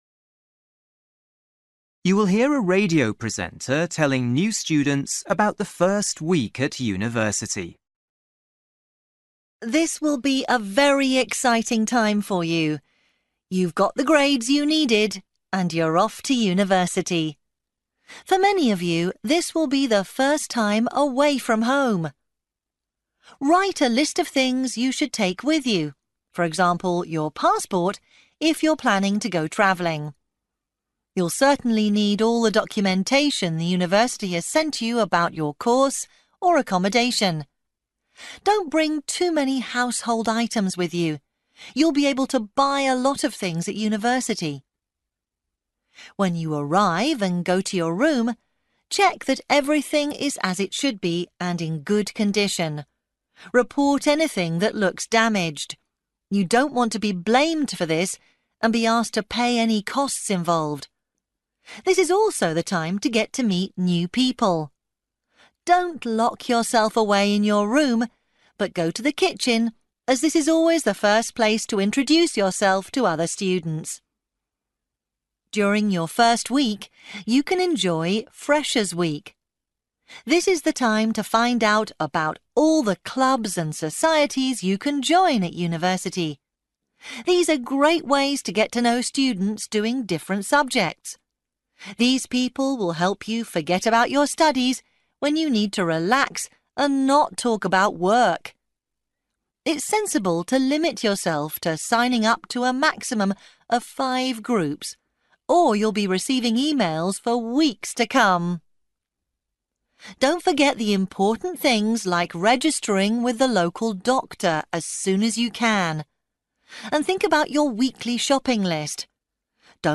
You will hear a radio presenter telling new students about the first week at university.